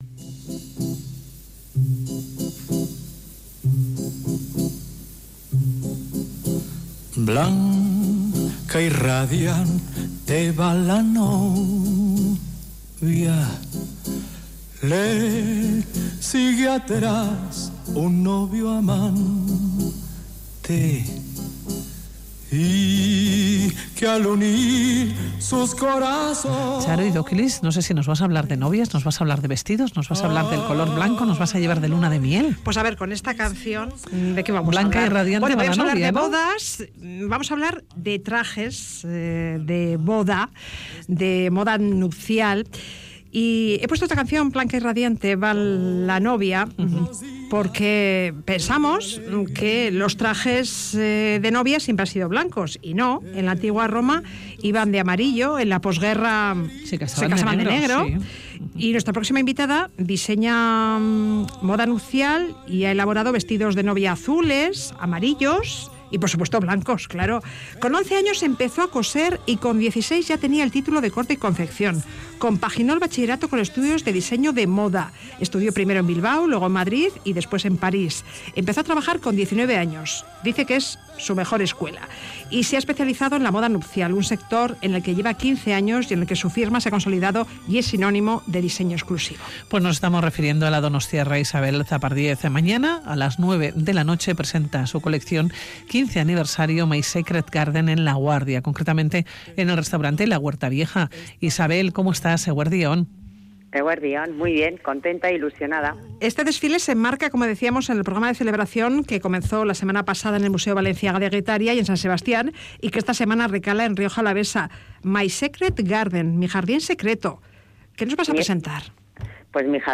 Hablamos con la donostiarra diseñadora de moda nupcial